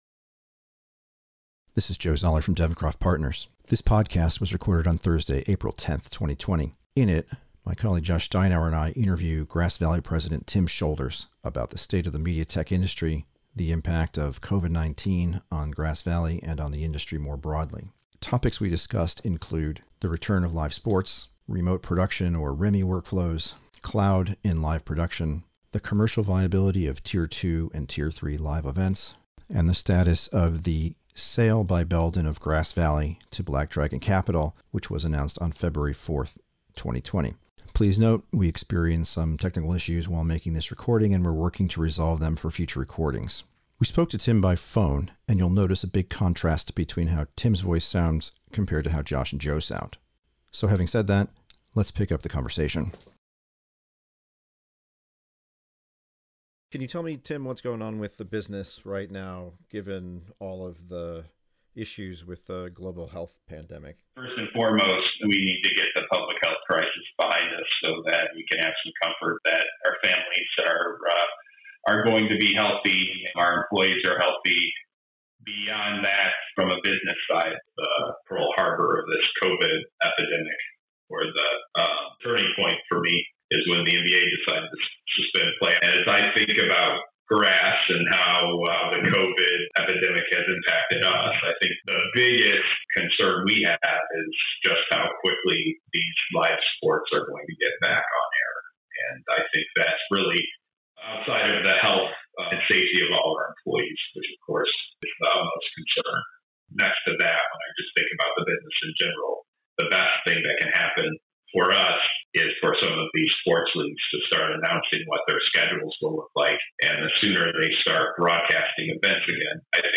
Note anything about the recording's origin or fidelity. (Again, we apologize for some of the technical issues with the audio recording, we will continue to work to resolve those in future podcasts.)